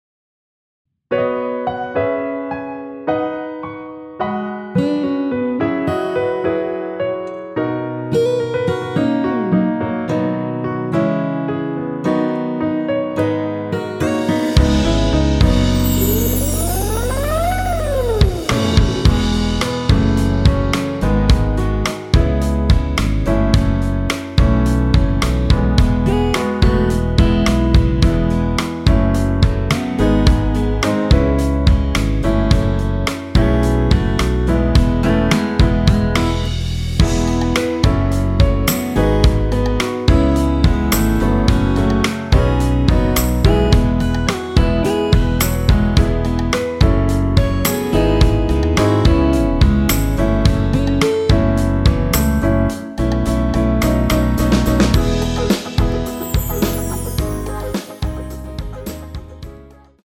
원키에서(-3)내린 MR입니다.
앞부분30초, 뒷부분30초씩 편집해서 올려 드리고 있습니다.
중간에 음이 끈어지고 다시 나오는 이유는
곡명 옆 (-1)은 반음 내림, (+1)은 반음 올림 입니다.